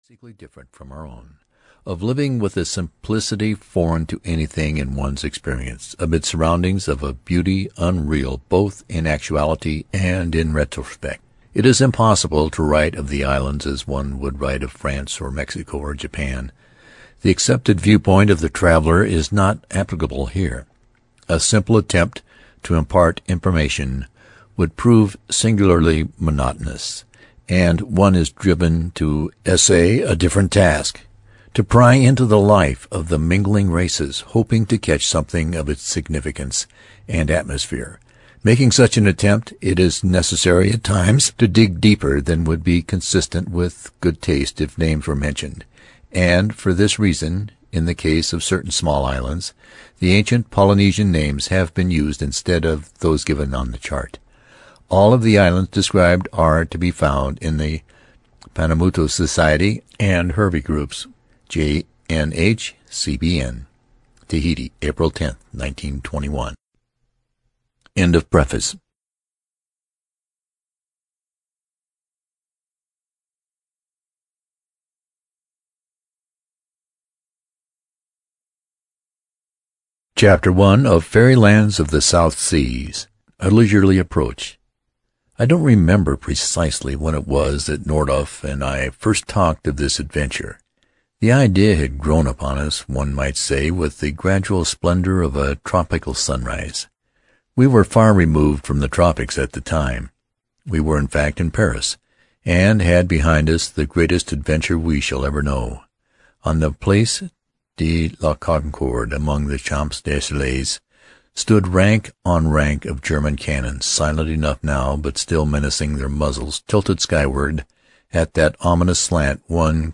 Faery Lands of the South Seas (EN) audiokniha
Ukázka z knihy